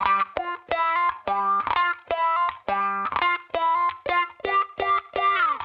Index of /musicradar/sampled-funk-soul-samples/85bpm/Guitar
SSF_StratGuitarProc2_85G.wav